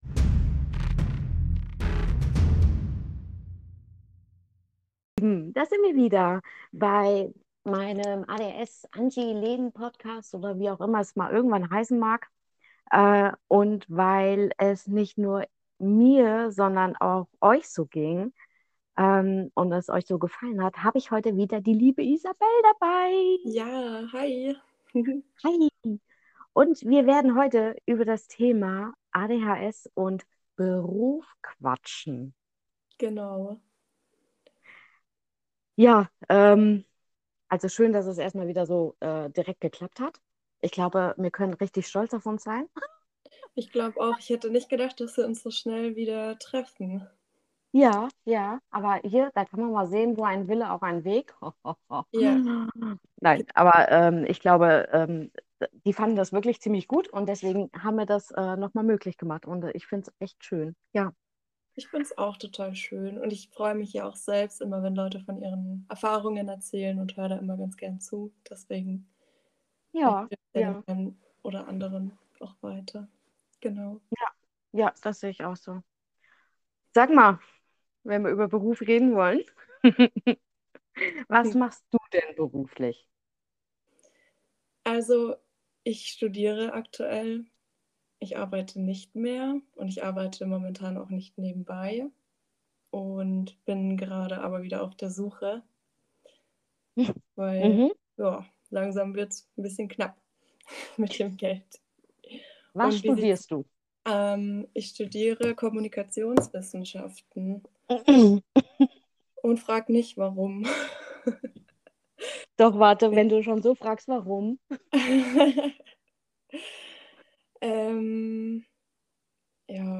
Einfach zwei betroffene spätdiagnostizierte Frauen mit ADHS